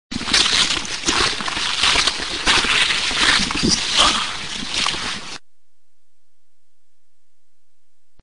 Alle Aufnahmen sind am Friedhof der Namenlosen entstanden und immer an der selben Stelle.
Am Beginn der Aufnahme hört man Schritte auf dem Kiesweg.